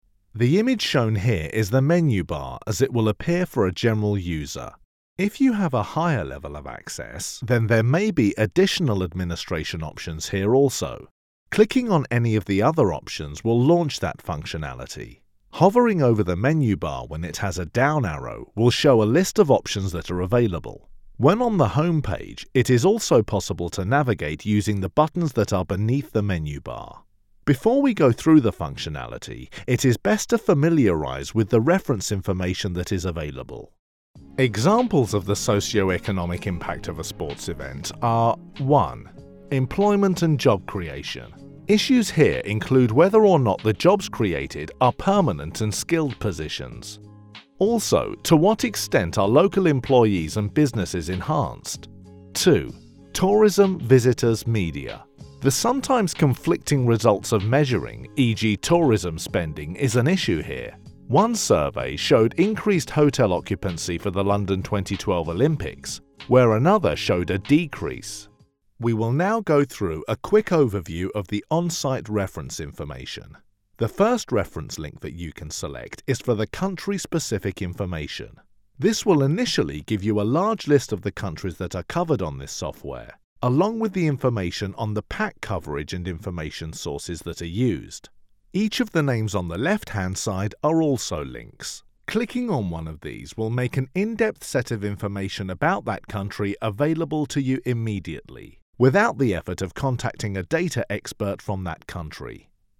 Current, relatable and versatile London voice. Characters, accents and impressions a specialty.
britisch
Sprechprobe: eLearning (Muttersprache):